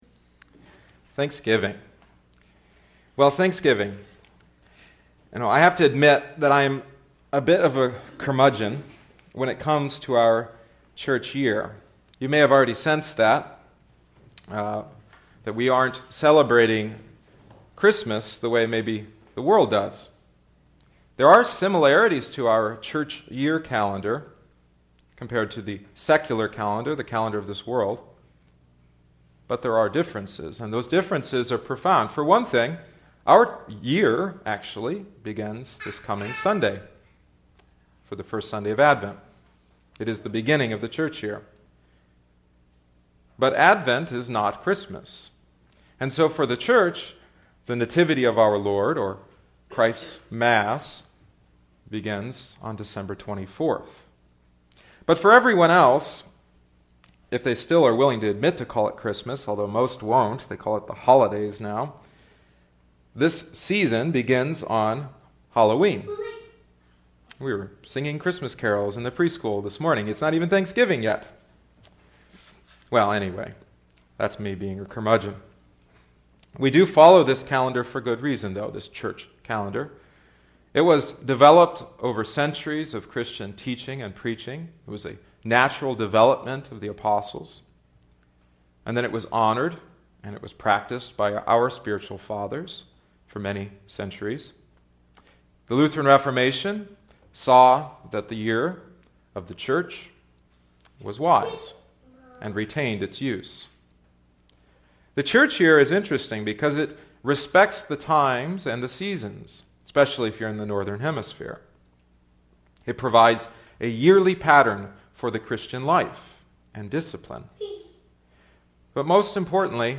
23. November 2011 Thanksgiving Eve Deuteronomy 26:1-11